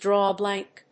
アクセントdráw a blánk